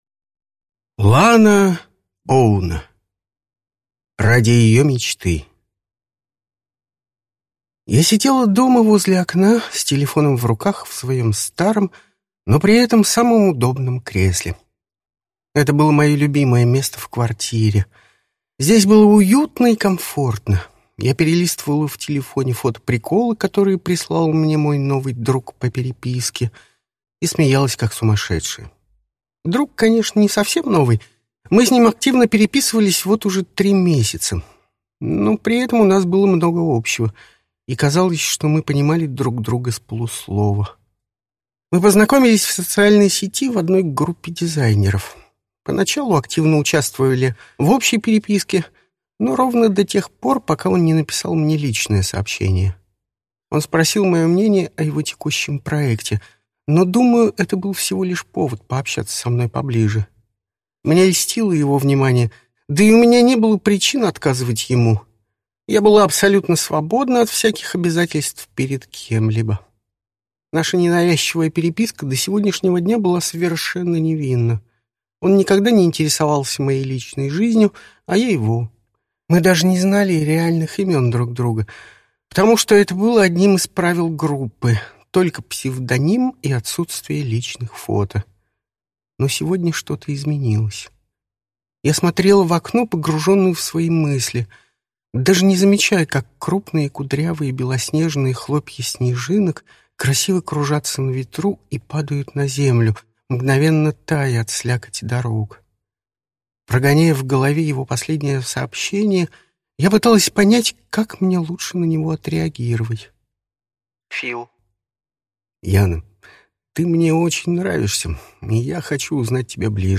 Аудиокнига Ради её мечты | Библиотека аудиокниг